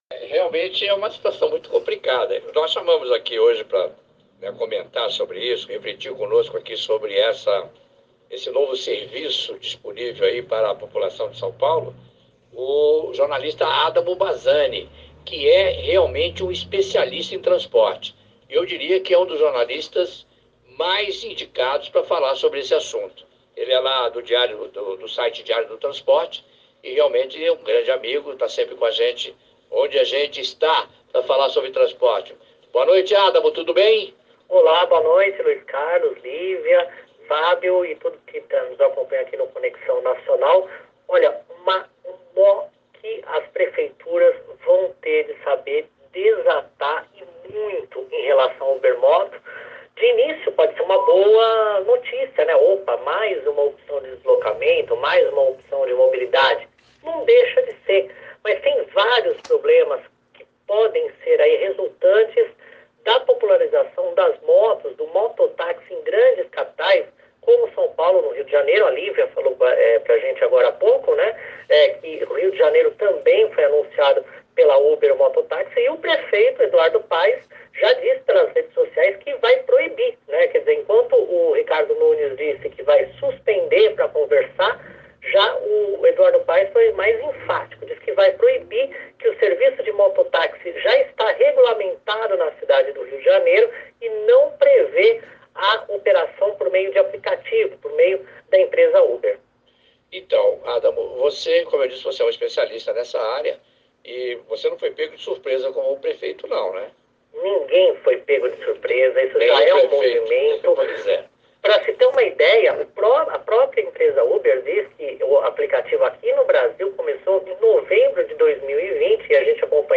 UBER-MOTOS-RADIO-CAPITAL.mp3